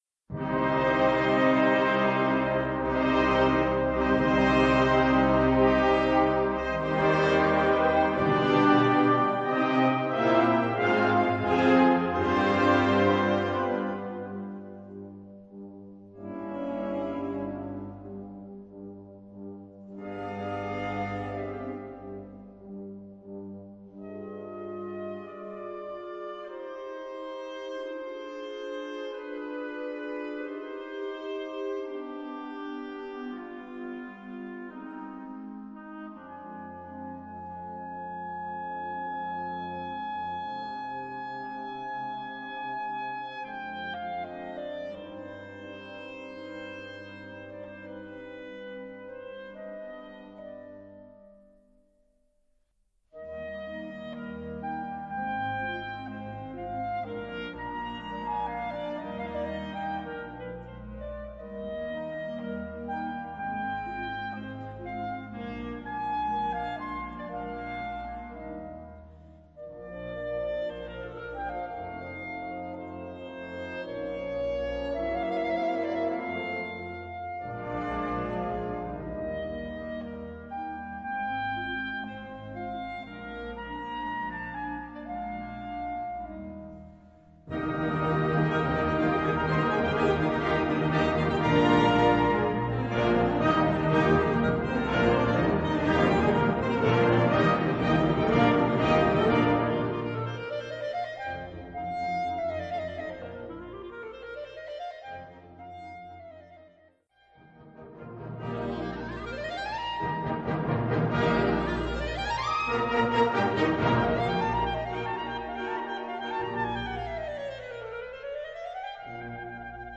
Gattung: Solo für Klarinette
Besetzung: Blasorchester